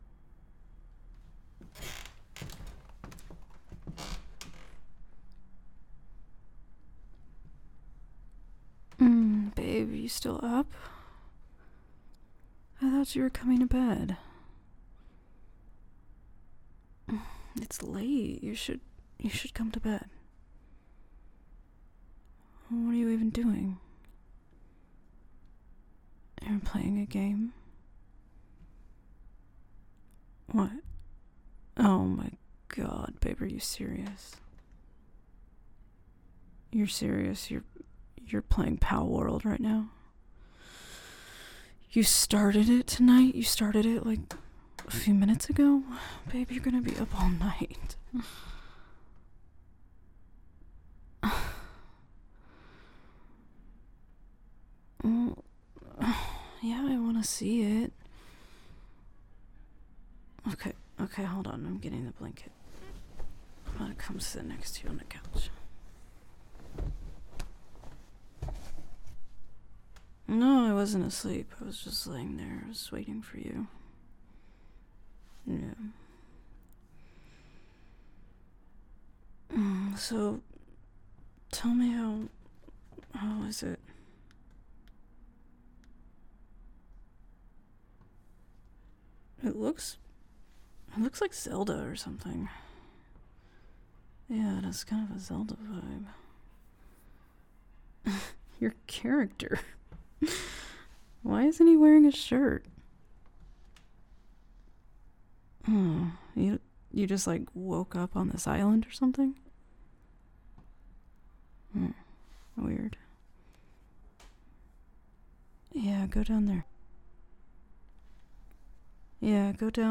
I liked the chair cracking and late night vibes. Very chill.